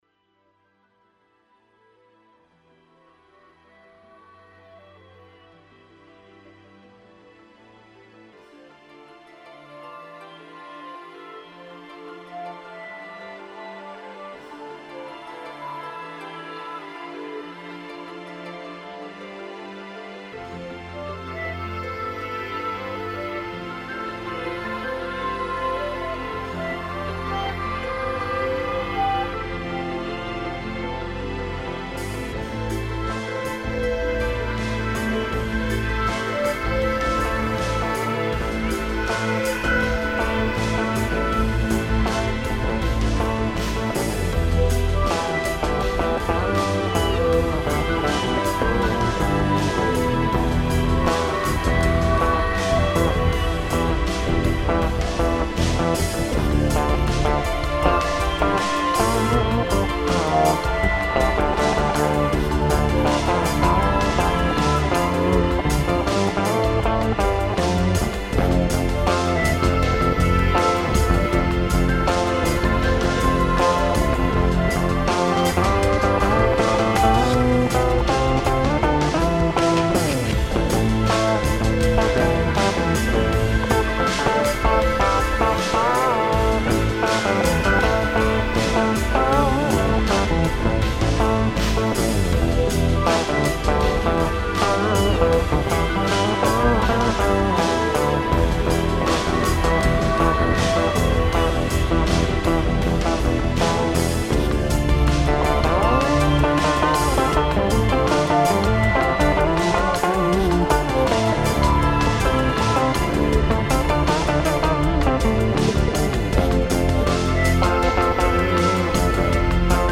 ARRIBA ESE BAIXÓTICO - DÍA DA PROCESIÓN DO CARME NA CASA CODA AUTÉNTICA REPETITIVE TRANCE MUSIC VIRTUHUMANA.mp3